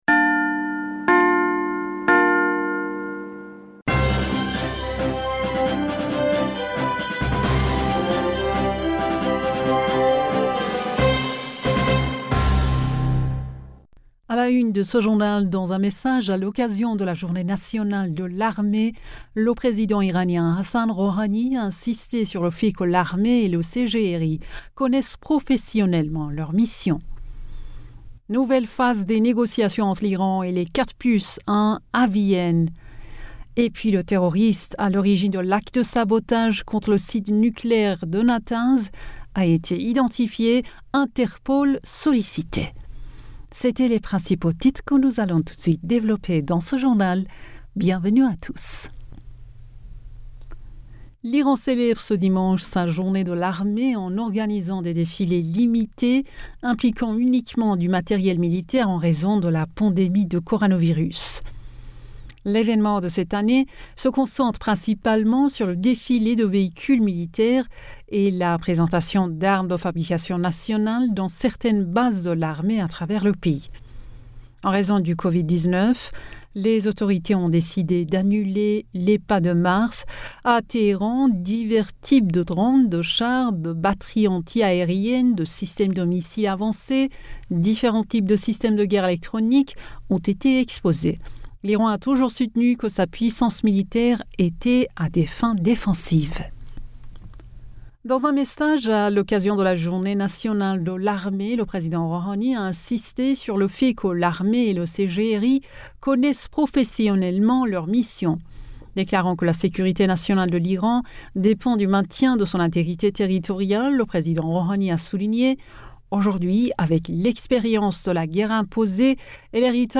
Bulletin d'information du 18 Avril 2021